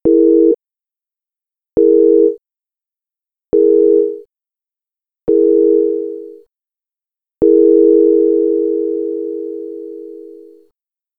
EXAMPLE: SR2 being slowly lowered, increasing release time: